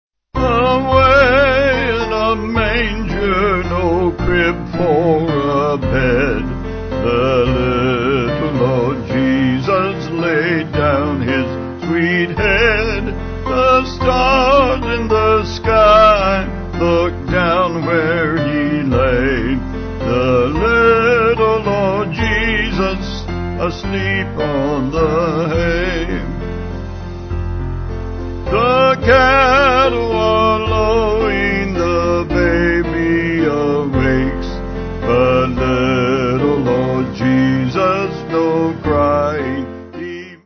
Vocals & Band